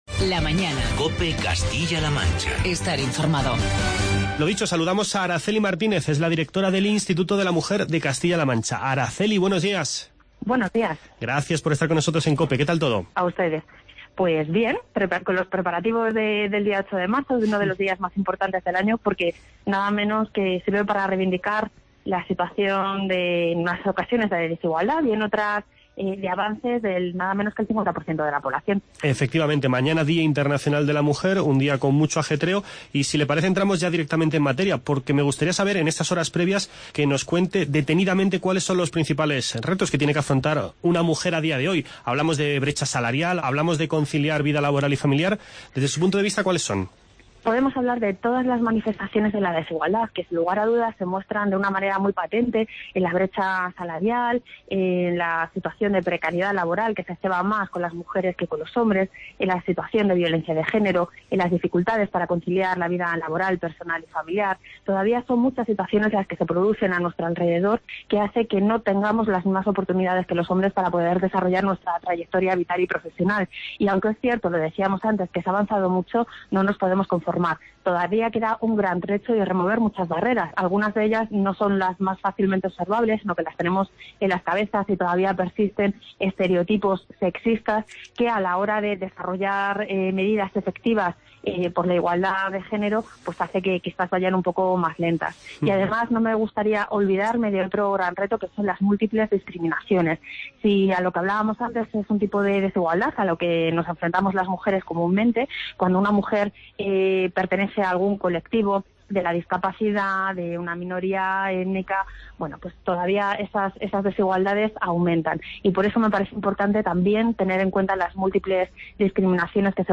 Programa especial con motivo del Día Internacional de la Mujer que celebraremos este martes, 8 de marzo. Escuche las entrevistas